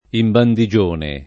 imbandigione